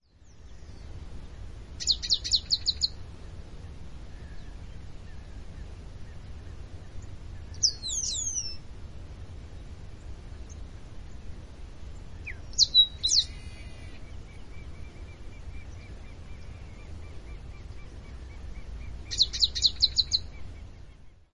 Yellow-winged Blackbird (Agelasticus thilius)
Sex: Male
Life Stage: Adult
Location or protected area: Lascano
Condition: Wild
Certainty: Observed, Recorded vocal
Agelasticus-thilius.mp3